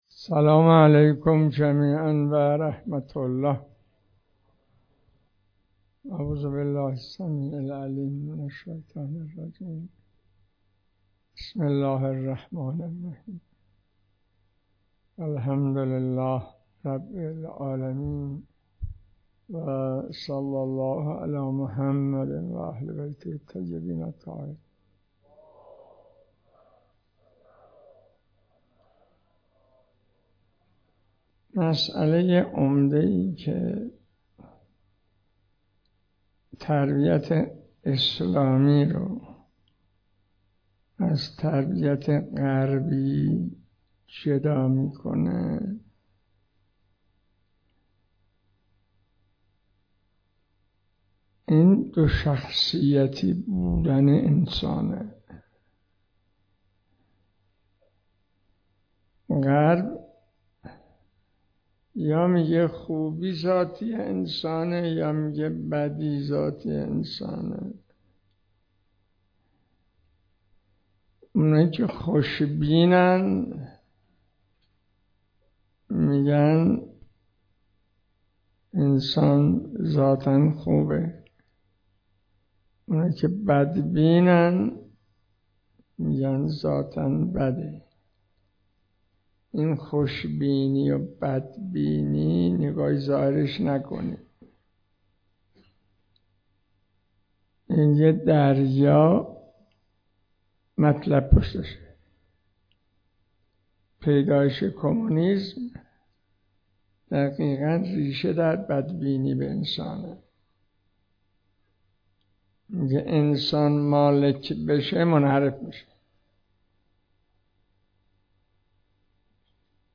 نشست تربیتی درمحضرحضرت آیت الله حائری شیرازی درخانه بیداری اسلامی - خانه بیداری اسلامی